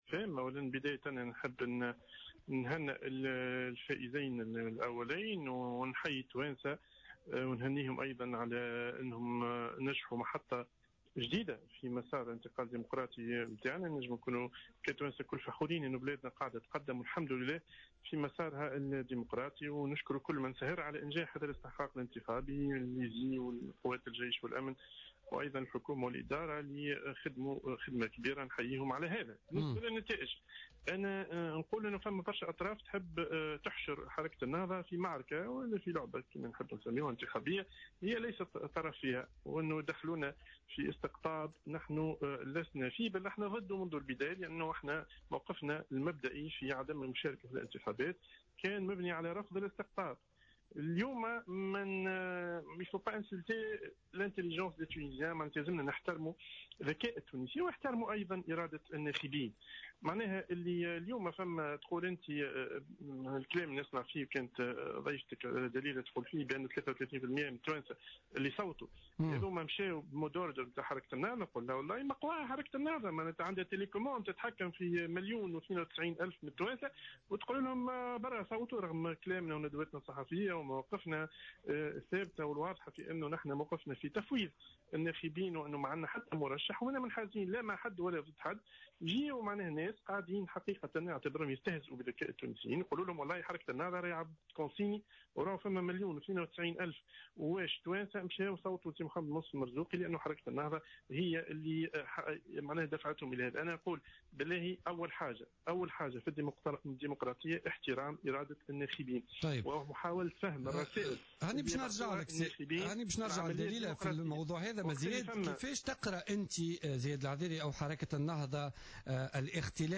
أكد الناطق الرسمي باسم حركة النهضة زياد العذراي في مداخلة له في برنامج بوليتيكا اليوم الثلاثاء 25 نوفمبر 2014 ان هناك أطراف تريد أن تحشر حركة النهضة في معركة ولعبة انتخابية هي ليست طرفا فيها وادخالها في استقطاب هي اعلنت رفضها له منذ البداية مشددا على ضرورة احترام ذكاء التونسيين وارادة الناخبين على حد قوله.